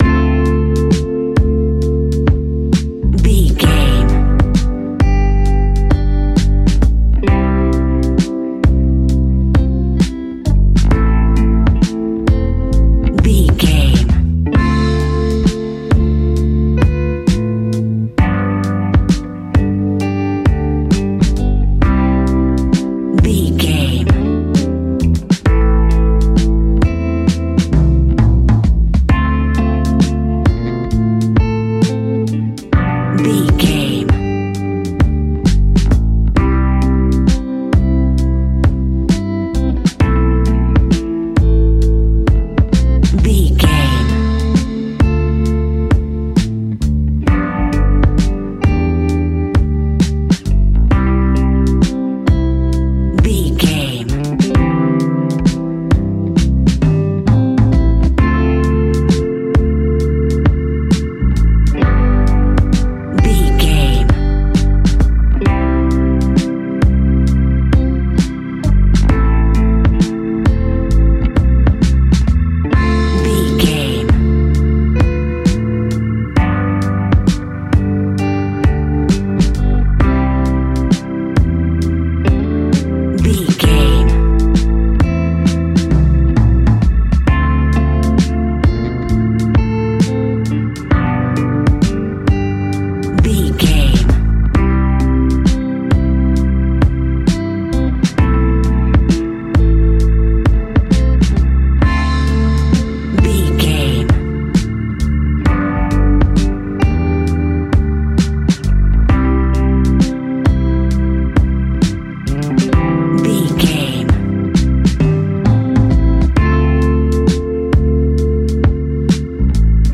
Ionian/Major
laid back
Lounge
sparse
new age
chilled electronica
ambient
atmospheric
instrumentals